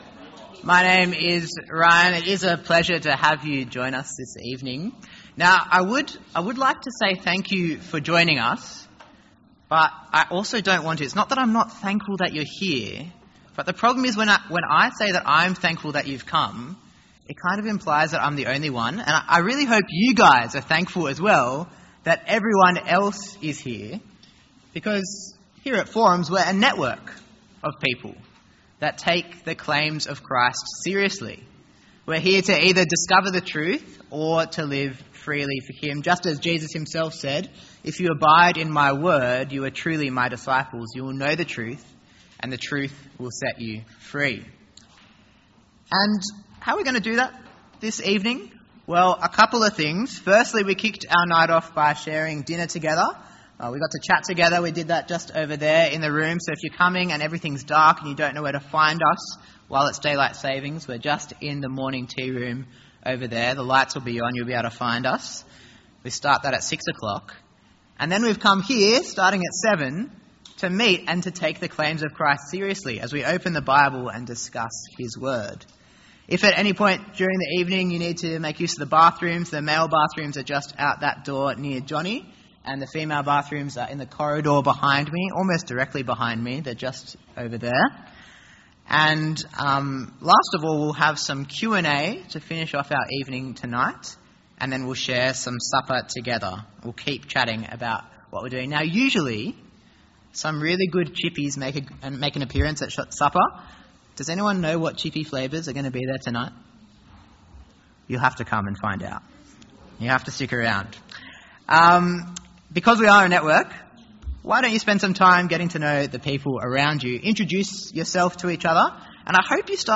An edited audio version of Week 1, Term 2 of Forum 2025. Forum is a 2 hour session of interactive Bible teaching for 18-30 year olds.